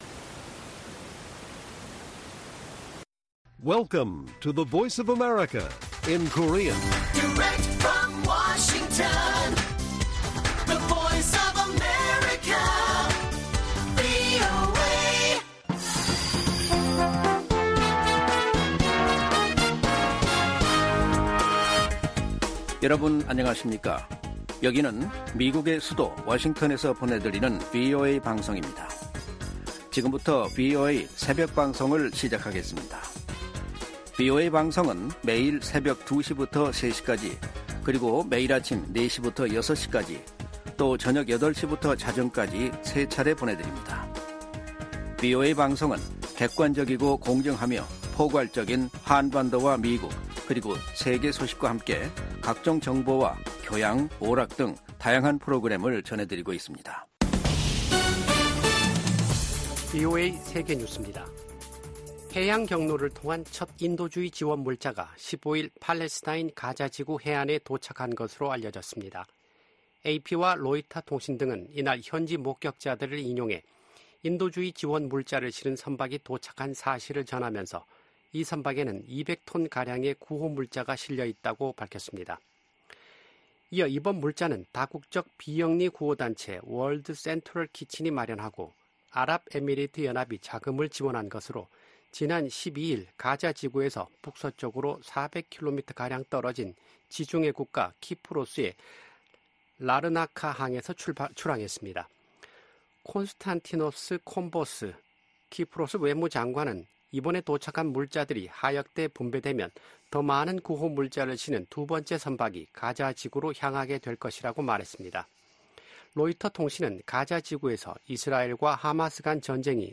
VOA 한국어 '출발 뉴스 쇼', 2024년 3월 16일 방송입니다. 토니 블링컨 미 국무장관이 한국 주최 제3차 민주주의 정상회의 참석을 위해 서울을 방문합니다.